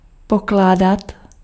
Ääntäminen
IPA: /de.po.ze/